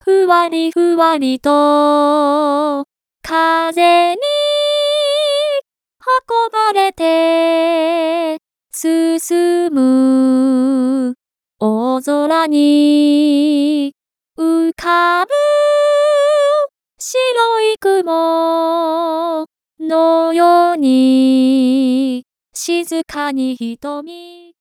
デフォルト（ベタ打ち）